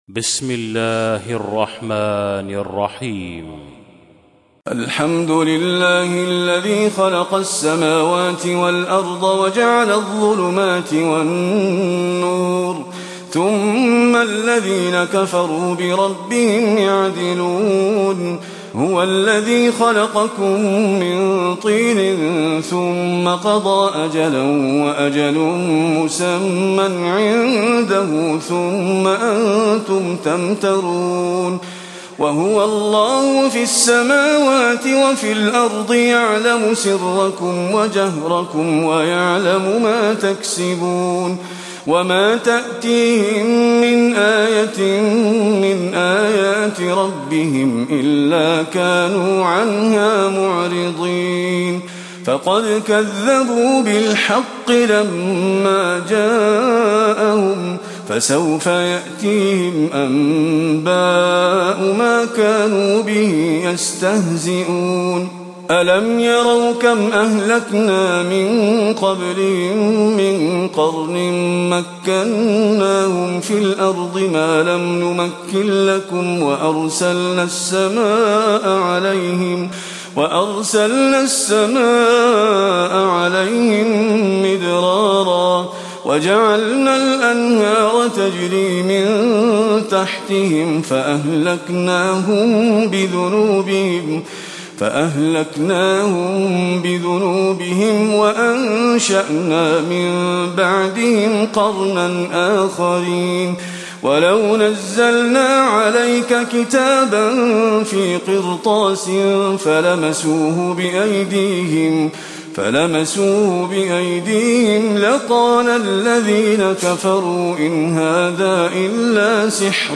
تلاوة سورة الأنعام من آية 1 إلى آية 58
تاريخ النشر ١ محرم ١٤٣٧ هـ المكان: المسجد النبوي الشيخ: فضيلة الشيخ محمد خليل القارئ فضيلة الشيخ محمد خليل القارئ سورة الأنعام (1-58)